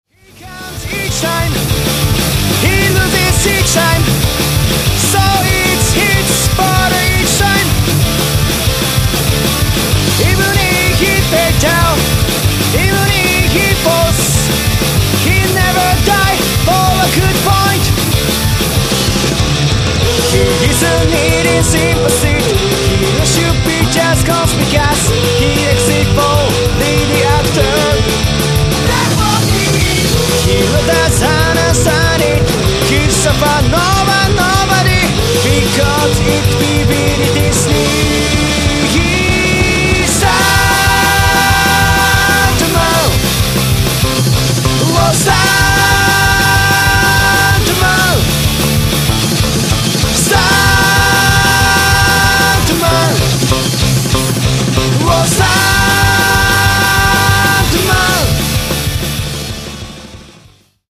Guitar
Bass
Drums & Percussion